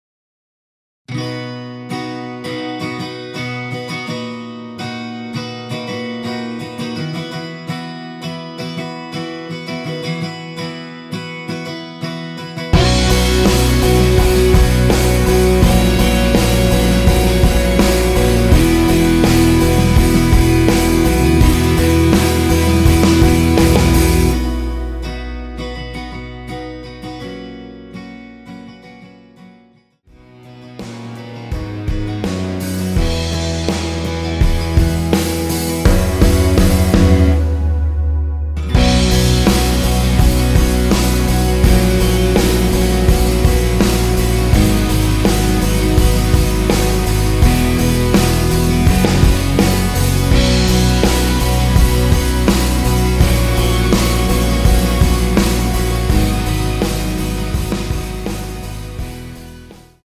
원키에서(-1)내린 (1절앞+후렴)으로 진행되게 편곡된 MR입니다.
◈ 곡명 옆 (-1)은 반음 내림, (+1)은 반음 올림 입니다.
앞부분30초, 뒷부분30초씩 편집해서 올려 드리고 있습니다.